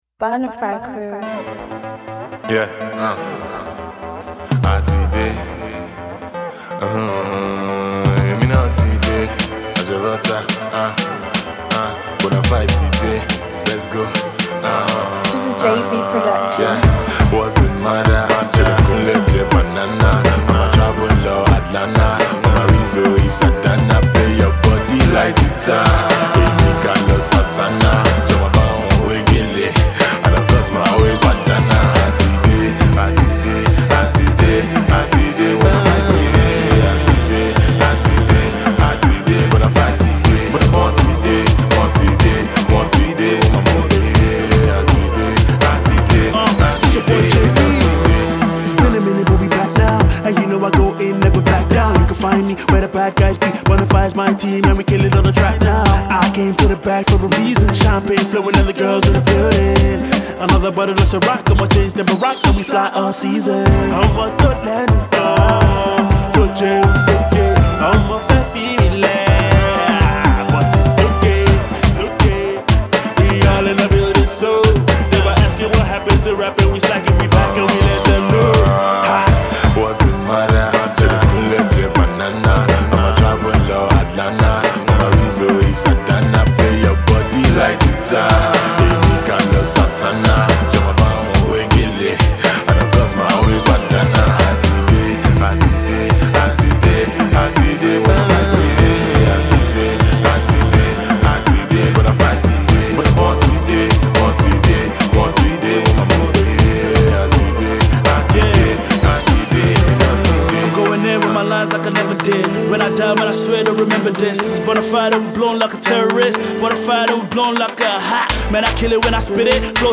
funky new tune